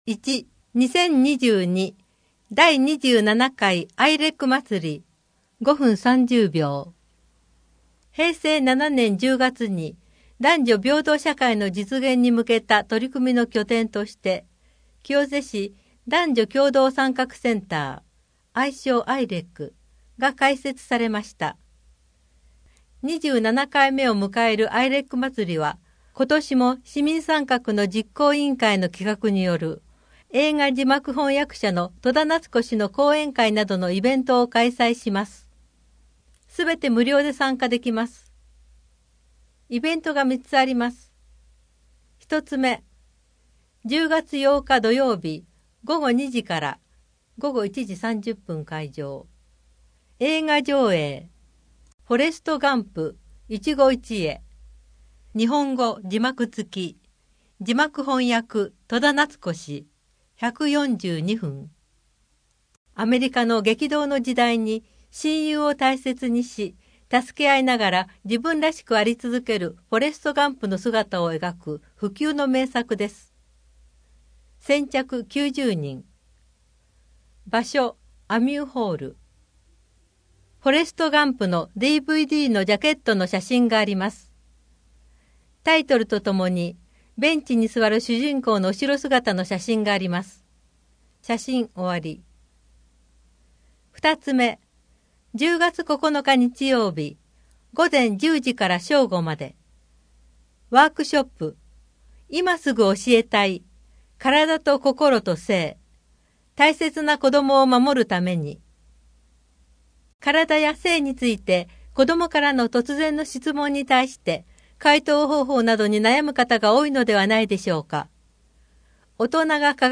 “カイツブリ”のヒナ誕生 清瀬市少林寺拳法連盟 各大会で大活躍 郷土博物館のイベント 特別展「古代武蔵と清瀬」 ミュージアムシアター5 清瀬けやきホールの催し物 コミュニティプラザひまわりの催し物 多摩六都科学館の催し物 人口と世帯 令和4年9月15日号8面 （PDF 1.4 MB） 声の広報 声の広報は清瀬市公共刊行物音訳機関が制作しています。